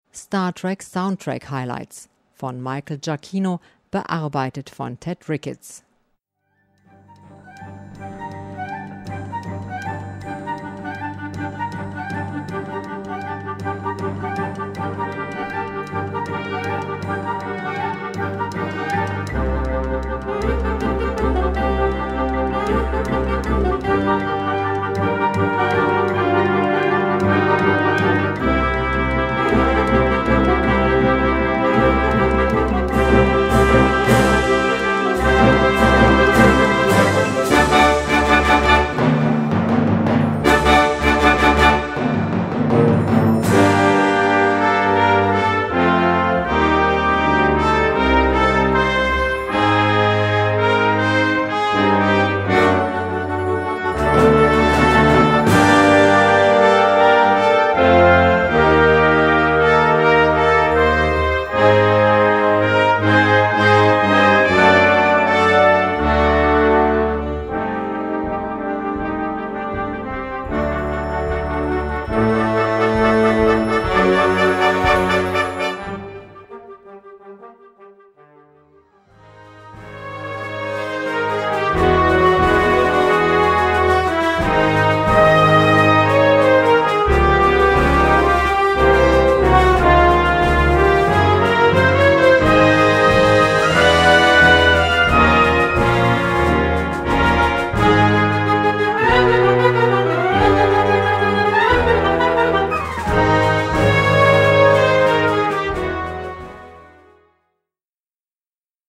Gattung: Medley
Besetzung: Blasorchester